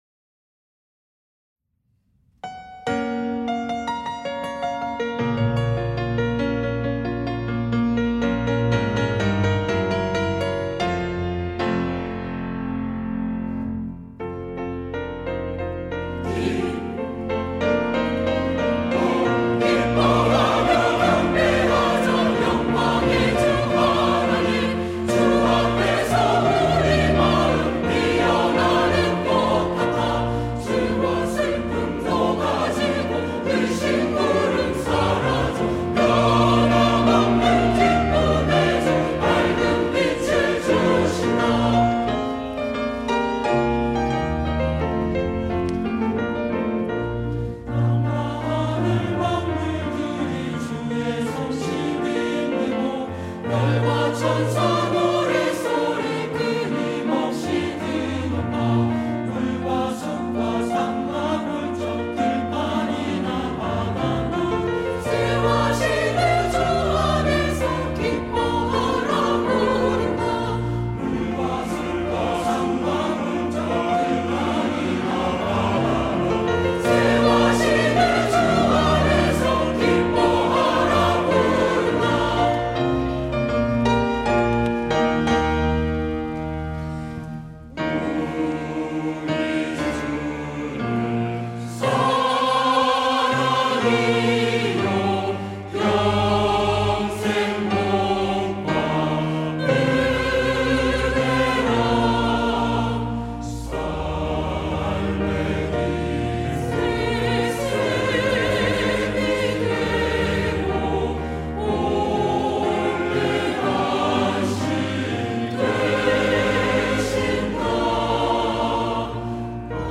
시온(주일1부) - 기뻐하며 경배하자
찬양대